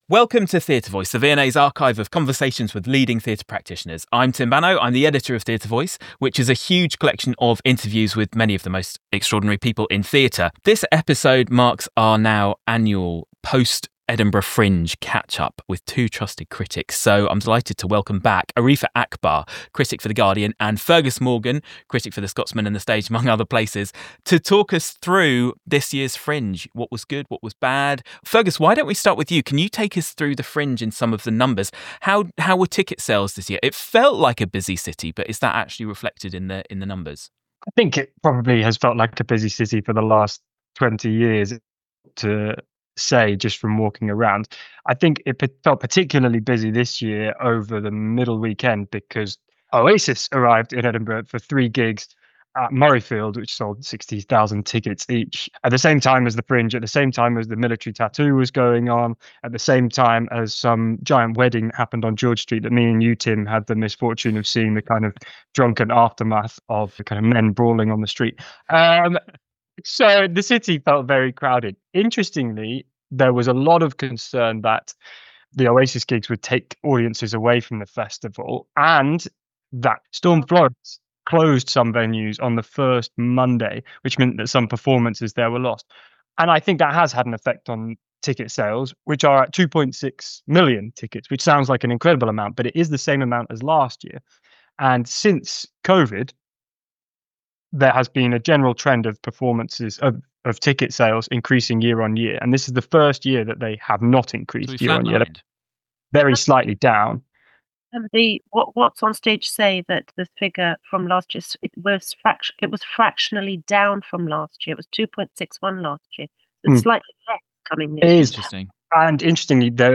This discussion was recorded on Zoom, September 11th 2025.